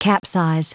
Prononciation du mot capsize en anglais (fichier audio)
Prononciation du mot : capsize